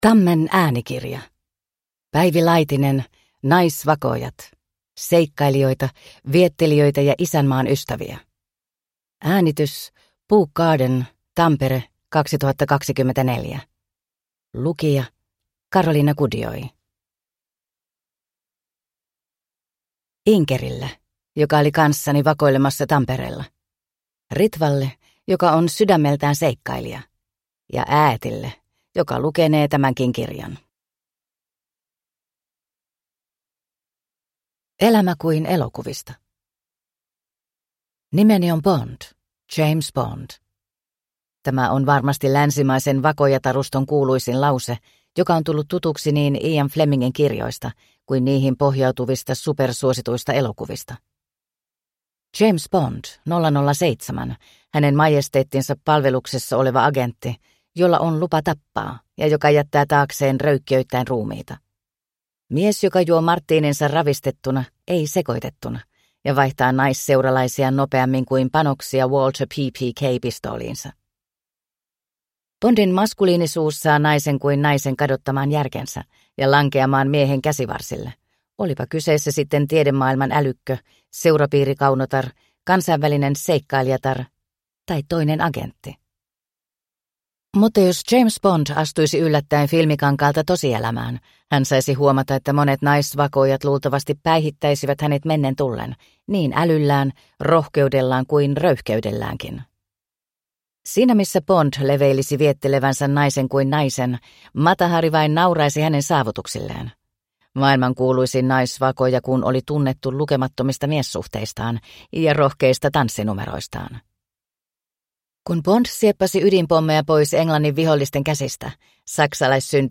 Naisvakoojat – Ljudbok